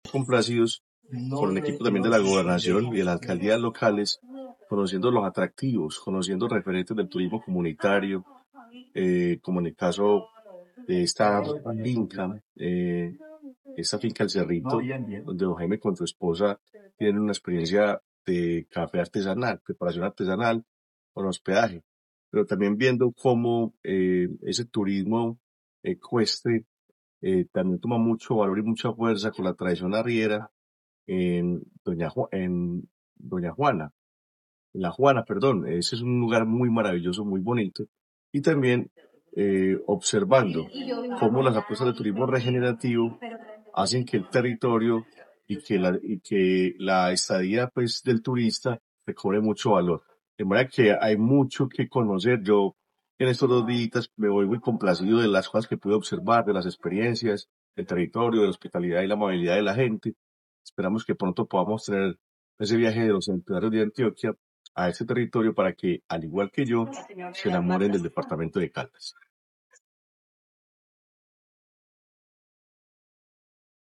Óscar Andrés Sánchez, director de Turismo de la Gobernación de Antioquia
director-de-Turismo-de-la-Gobernacion-de-Antioquia-Oscar-Andres-Sanchez.mp3